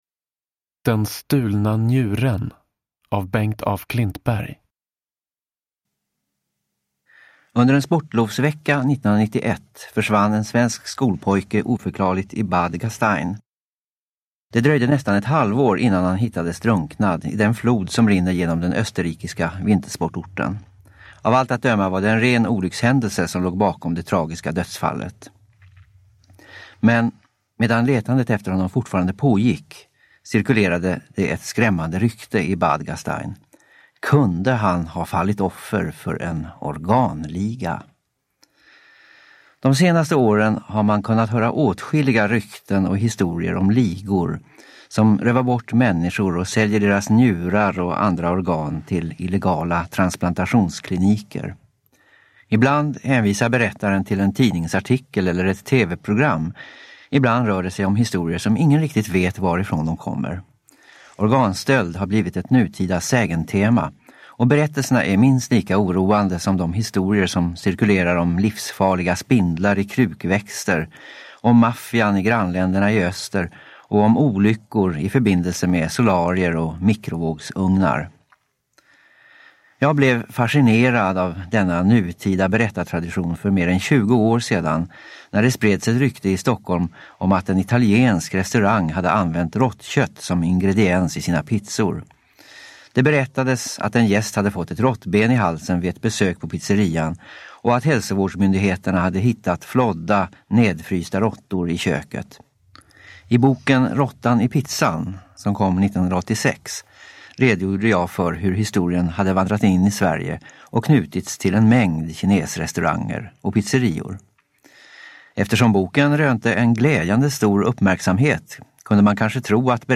Den stulna njuren : sägner och rykten i vår tid – Ljudbok – Laddas ner
Originalinspelningen gjordes 1994.
Uppläsare: Anita Wall, Bengt af Klintberg